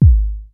VCF BASE 2 2.wav